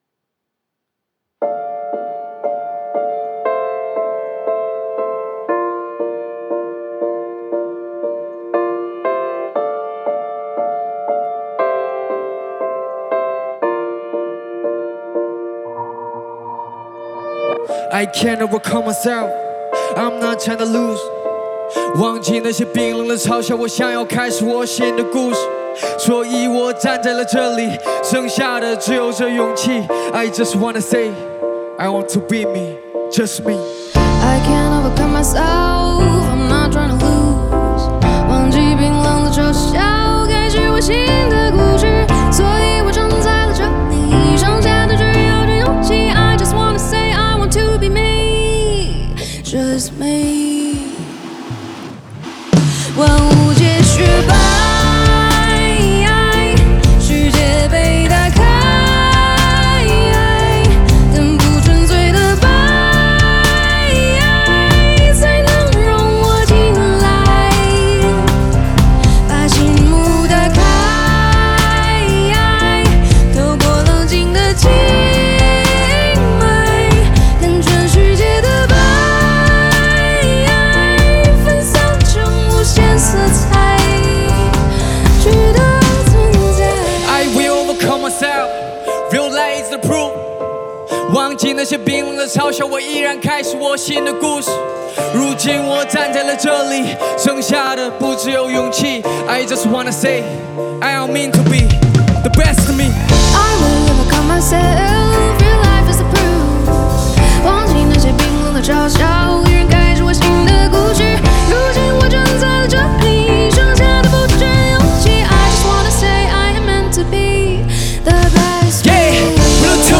Ps：在线试听为压缩音质节选，体验无损音质请下载完整版
(Live)